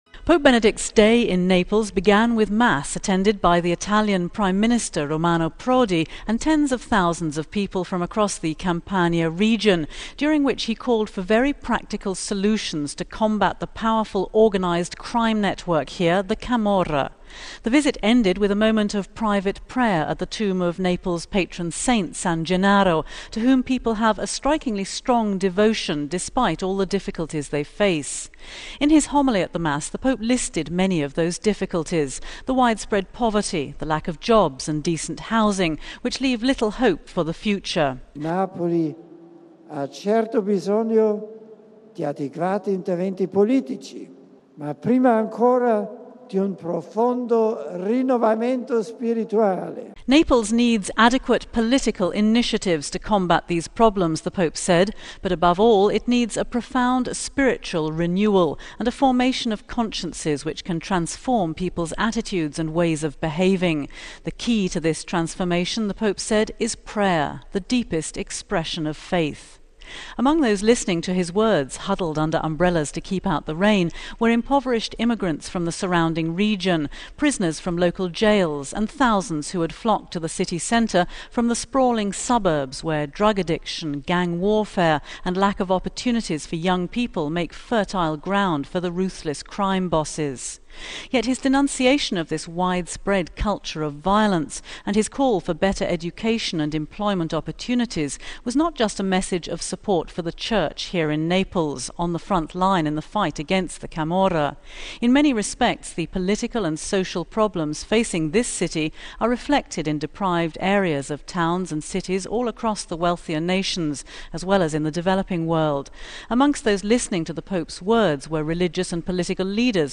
bringing us interviews with some of the participants including a Burmese monk